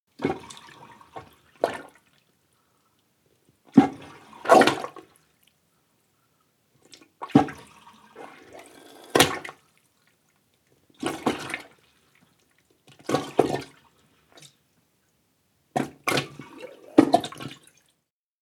Toilet Plunger Sound
household